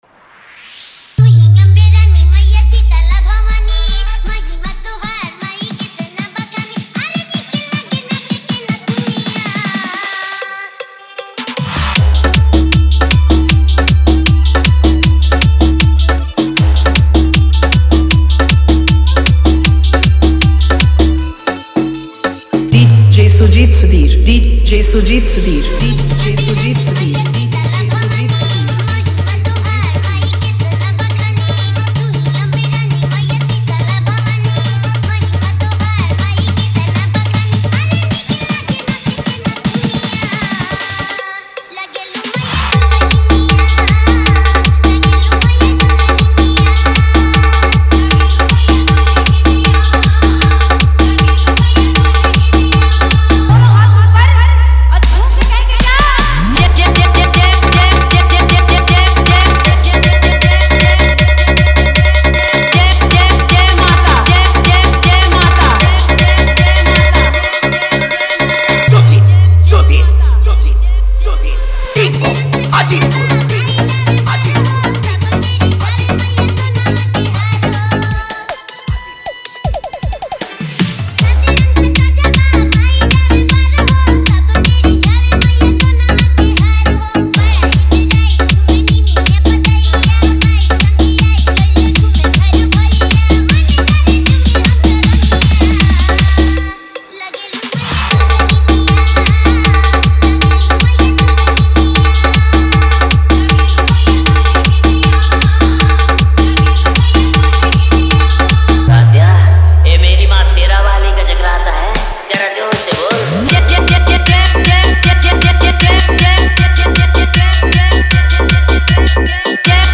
Navratri Dj Song